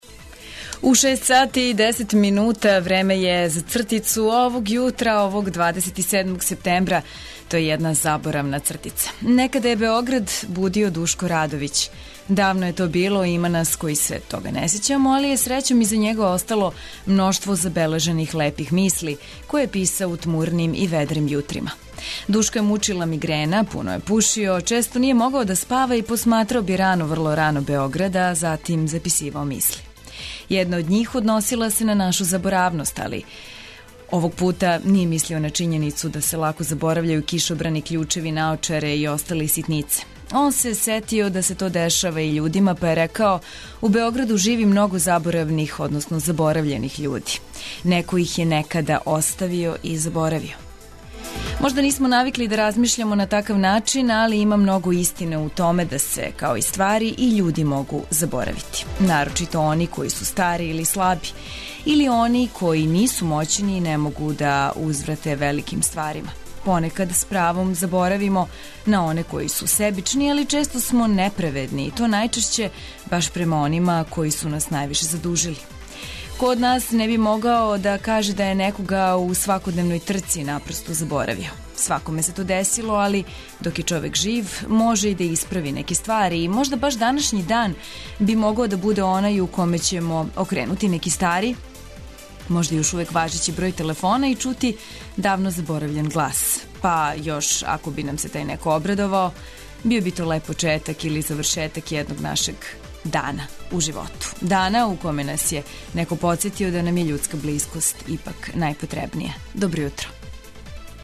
Потрудићемо се да будемо романтични већ изјутра па вам шаљемо радијску слику из сеновитог Кошутњака. То је место где се традиционално мери температура ваздуха и где је седиште Хидрометеоролошког завода.